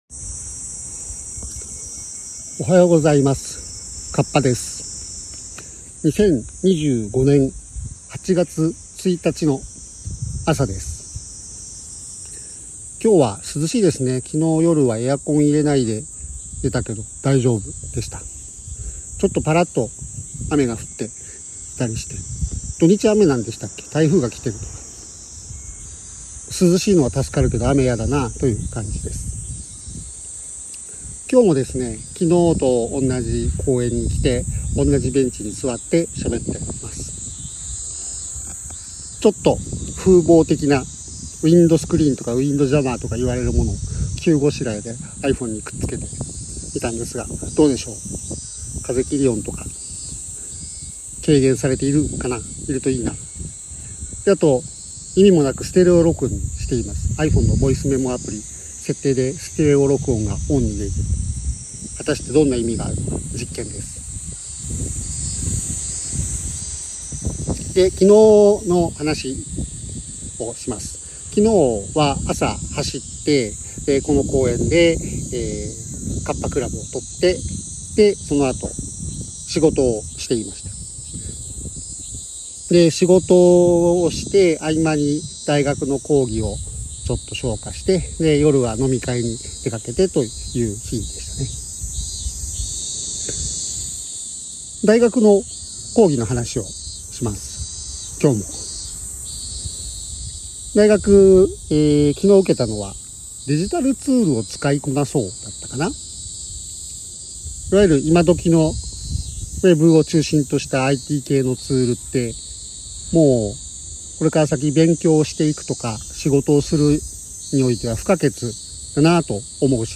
Blender、コーチングとマネジメント、飲み会、同人誌などの話をしました。風切音の対策をしたんだけど、まだ不十分ですね。